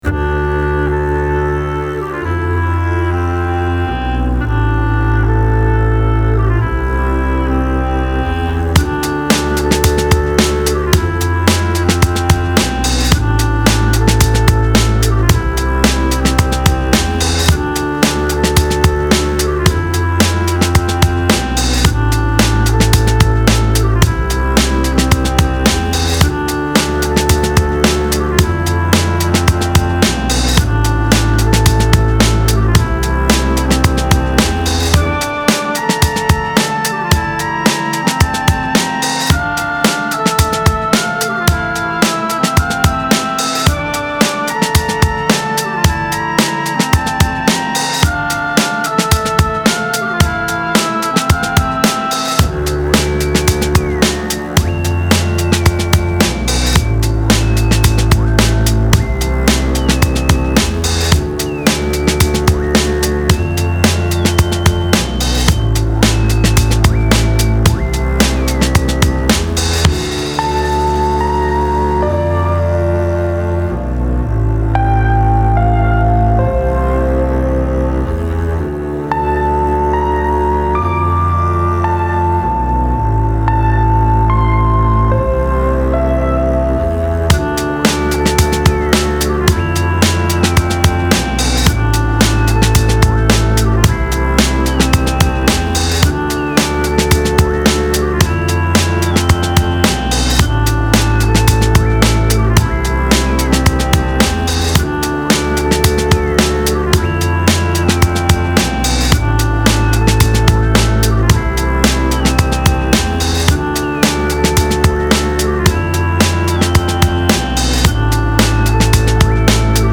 Style Style Ambient, EDM/Electronic
Mood Mood Cool, Relaxed
Featured Featured Bass, Mellotron, Piano +2 more
BPM BPM 110